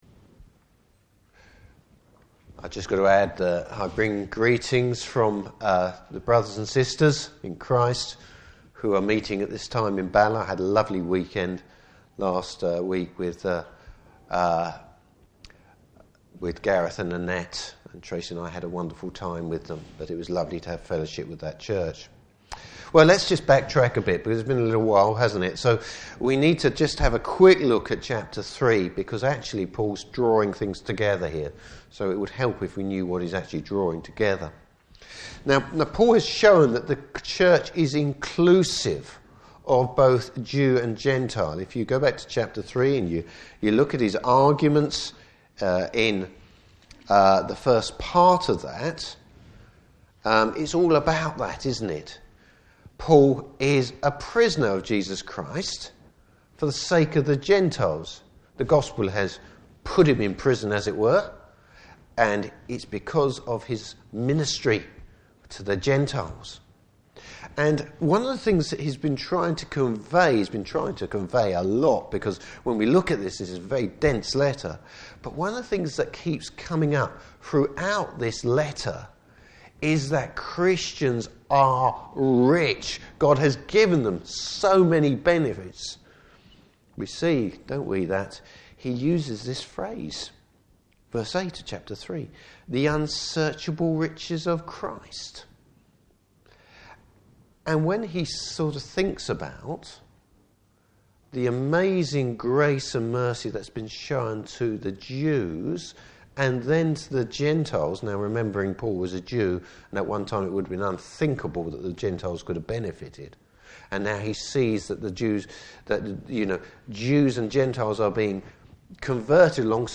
Service Type: Morning Service Bible Text: Ephesians 4:1-19.